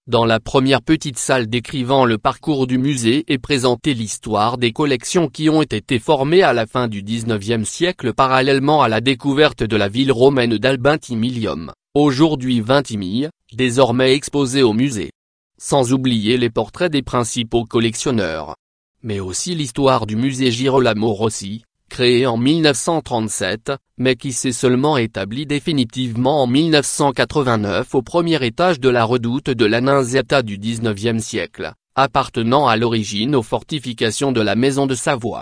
FR Audioguide Salle 1